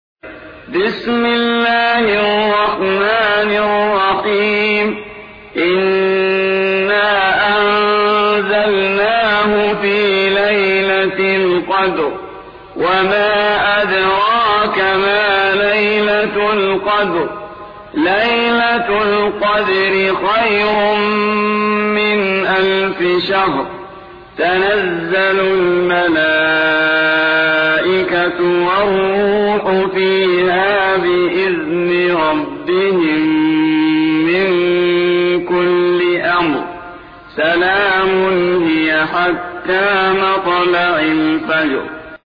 97. سورة القدر / القارئ